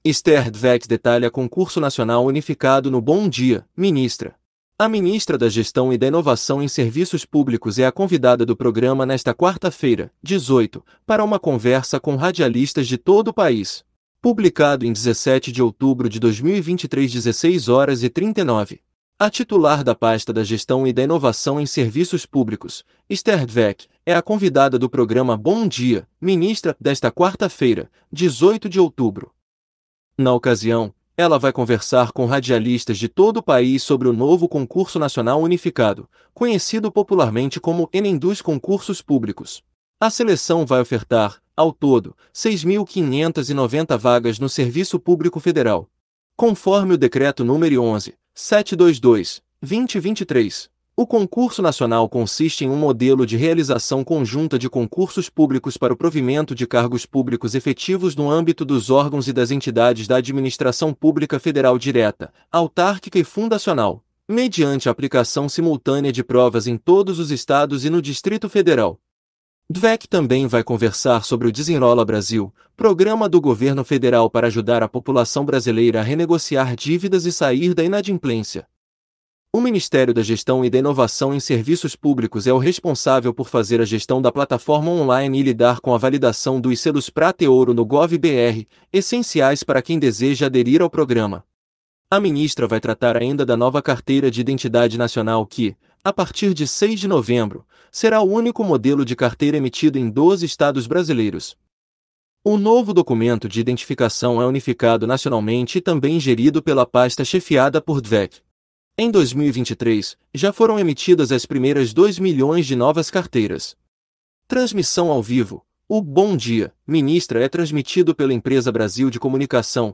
A ministra da Gestão e da Inovação em Serviços Públicos é a convidada do programa nesta quarta-feira (18) para uma conversa com radialistas de todo o país